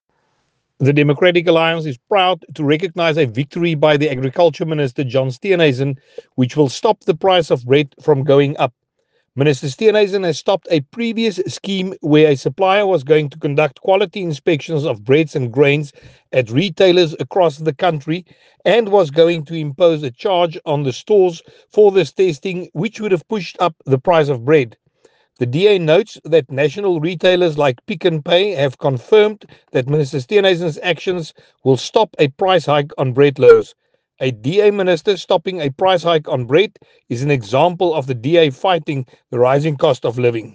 soundbite by Willie Aucamp MP.